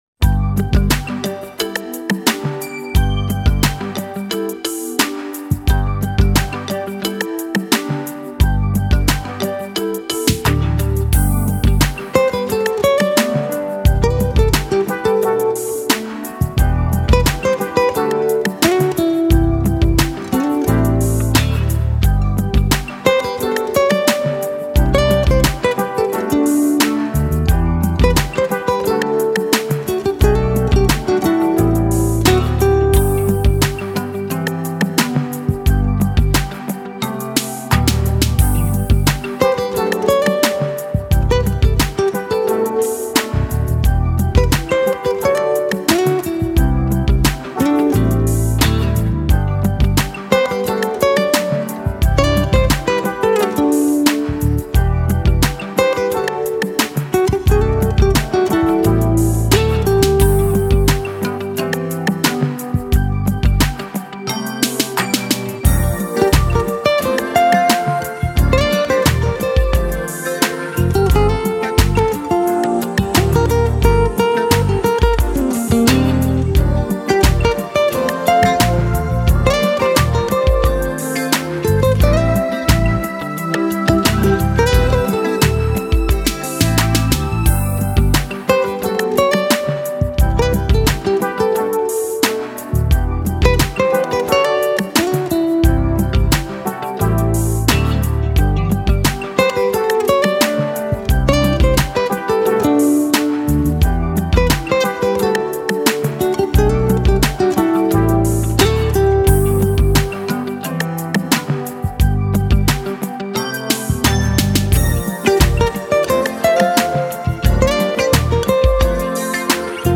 Грациозная высокотоновая